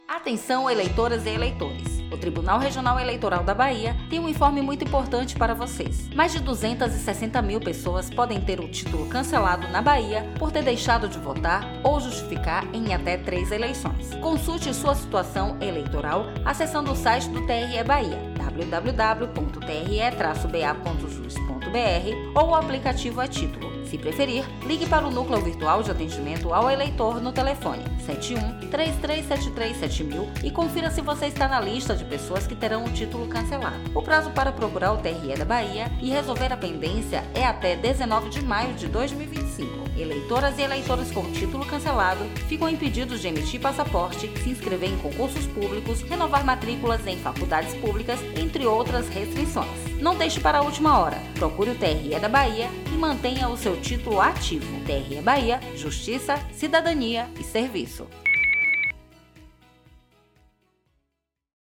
Noticia oXarope 28abr2501tituloeleitor